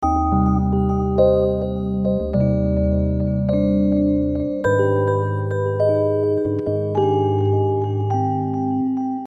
描述：Rhodes, Delay, and a bad mood.
Tag: 104 bpm Chill Out Loops Organ Loops 1.55 MB wav Key : Unknown